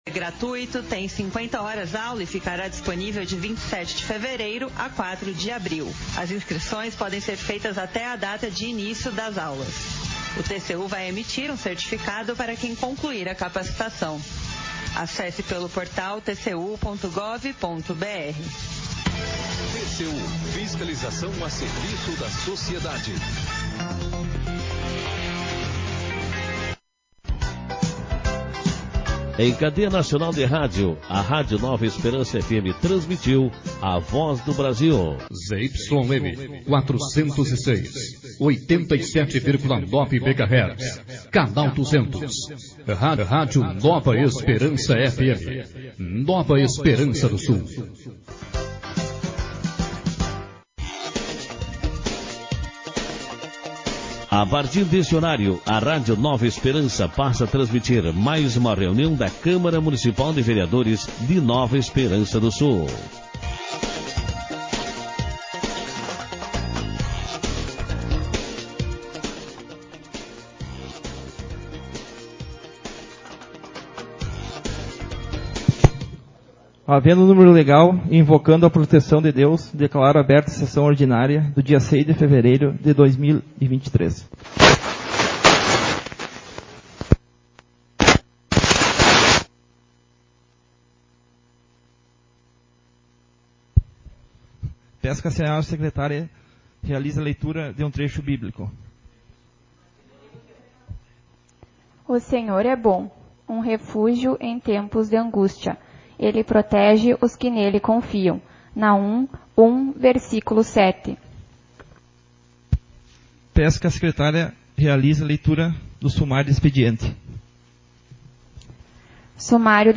Sessão Ordinária 01/2023